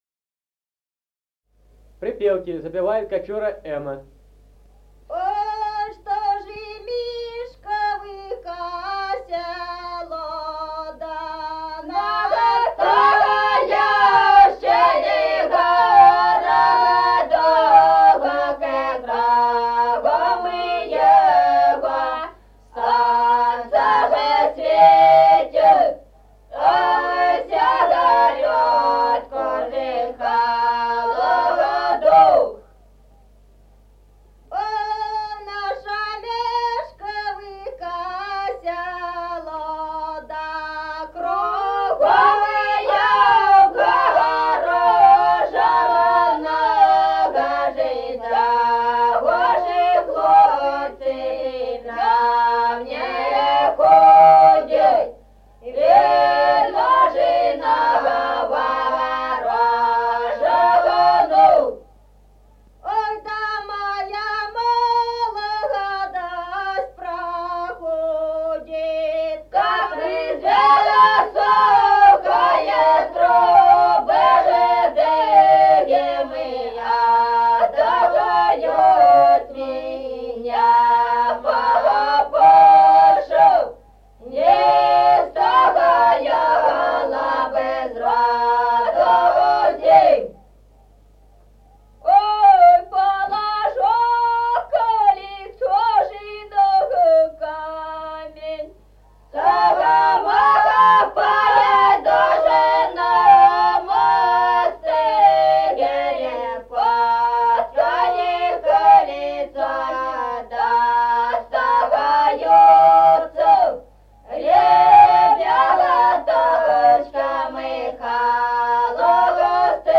Музыкальный фольклор села Мишковка «Ой, что ж Мишковка-село», припевки.